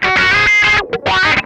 MANIC WAH 4.wav